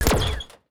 UIClick_Menu Laser Hit Rustle Tail 01.wav